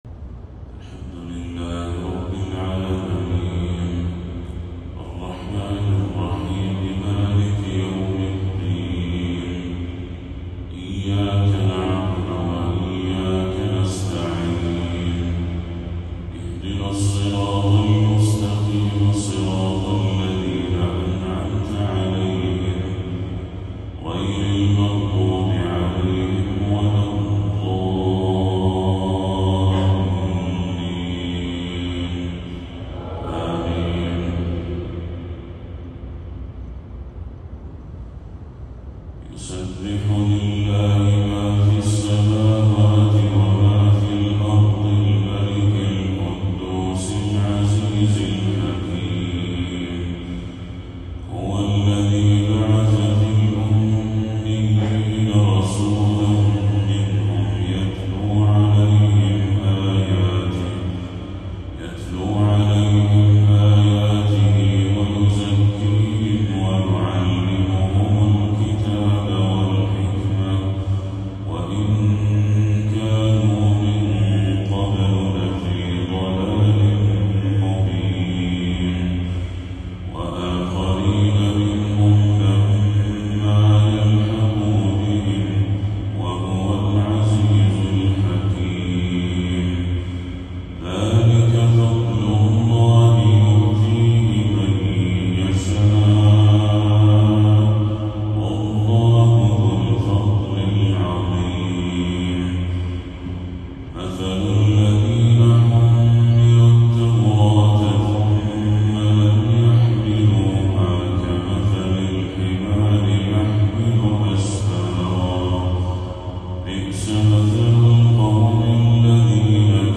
تلاوة لسورتي الجمعة والتكوير
فجر 13 ربيع الأول 1446هـ